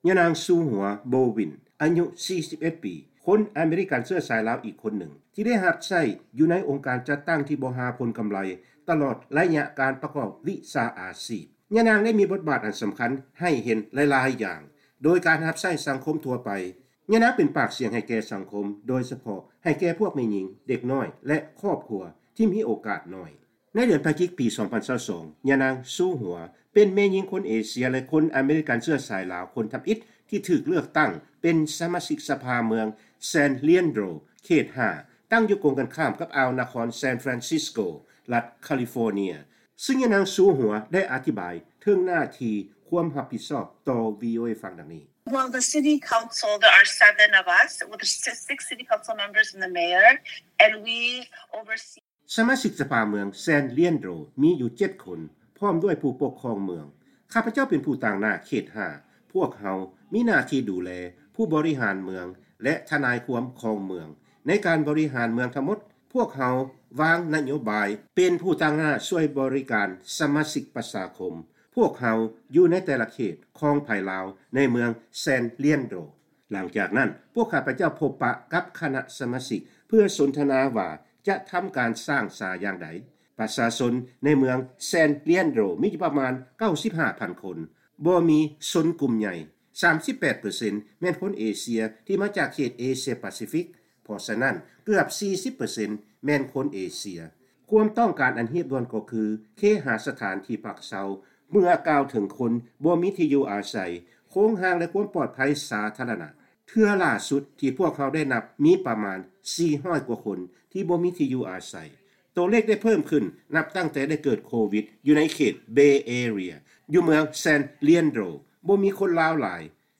ໃນລາຍການຊີວິດຊາວລາວ ໃນຕ່າງແດນຂອງວີໂອເອ ສຳລັບແລງວັນພະຫັດມື້ນີ້ ເຮົາຈະນຳເອົາການໂອ້ລົມ ກັບຍານາງຊູຫົົວ ໂບເວີນ ອາຍຸ 41 ປີ ຄົນອາເມຣິກັນເຊື້ອສາຍລາວ ຊຶ່ງໃນປັດຈຸບັນນີ້ຍານາງຊິວຫົວ ເປັນສະມາຊິກສະພາເມືອງແຊນ ລຽນໂດຣ (San Leandro) ໃນລັດຄາລິຟໍເນຍ.